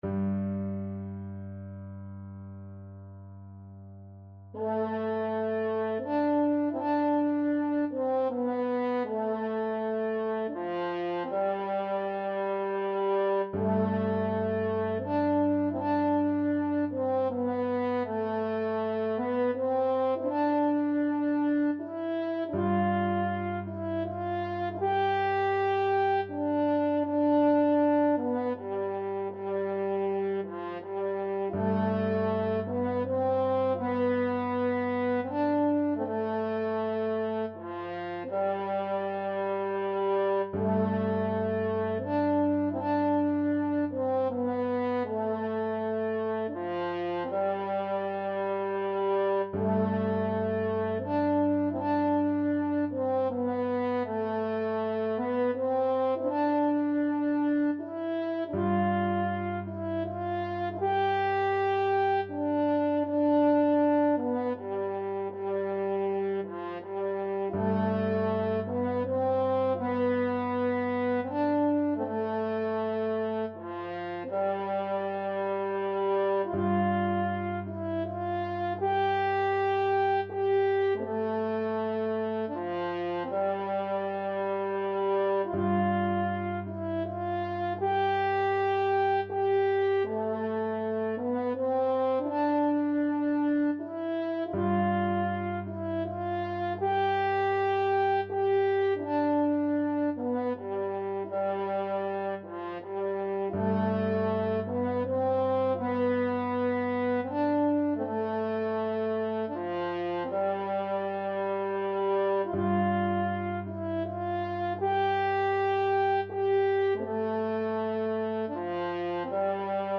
Traditional Trad. Sleep Dearie Sleep (as used in the funeral of Queen Elizabeth II) French Horn version
French Horn
3/4 (View more 3/4 Music)
C major (Sounding Pitch) G major (French Horn in F) (View more C major Music for French Horn )
Slowly and freely, in the manner of bagpipes =80
Traditional (View more Traditional French Horn Music)